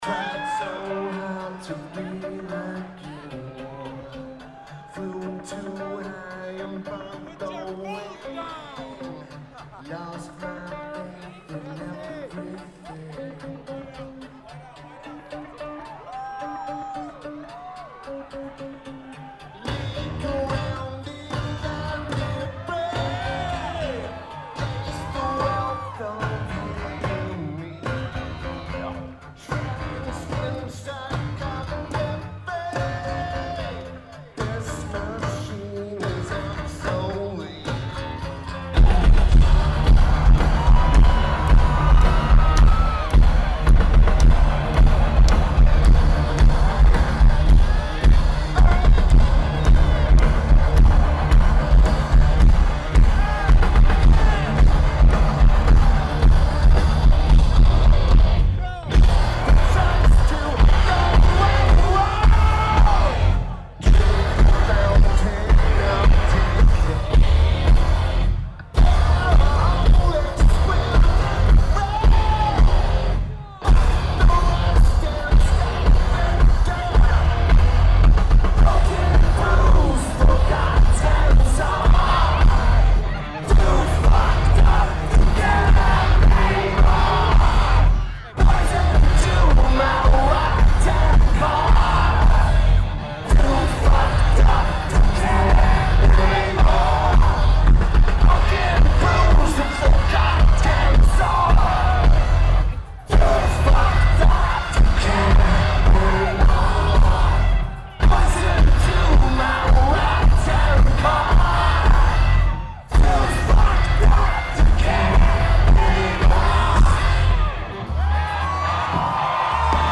The Palladium
Lineage: Audio - AUD (DPA 4061 + 9V Battery + Tascam DR2D)